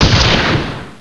enemydie.wav